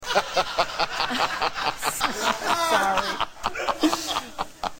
Tom Snyder - Laughing
Category: Radio   Right: Personal